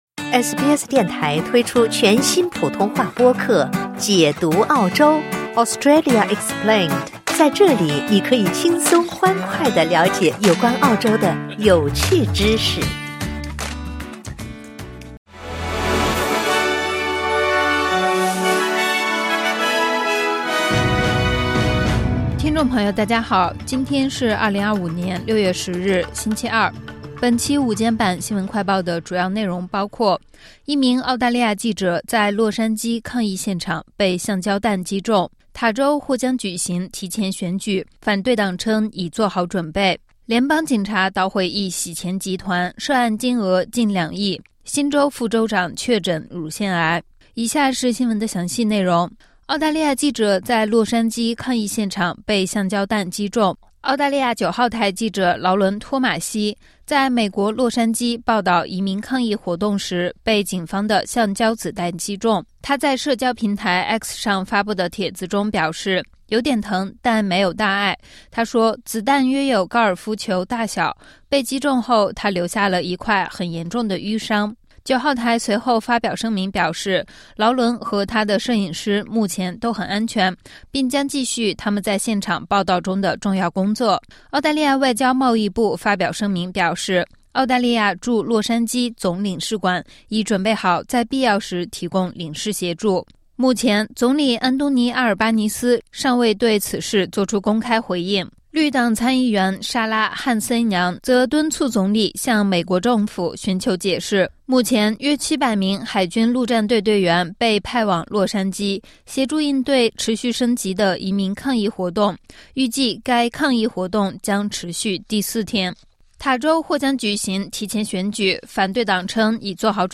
【SBS新闻快报】澳洲记者在洛杉矶抗议现场被橡胶弹击中